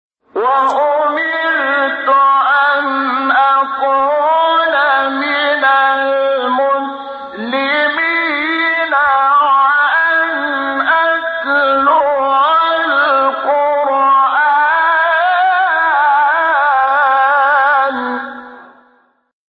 سایت قرآن کلام نورانی - چهارگاه انور شحات (1).mp3
سایت-قرآن-کلام-نورانی-چهارگاه-انور-شحات-1.mp3